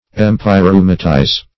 Empyreumatize \Em`py*reu"ma*tize\, v. t.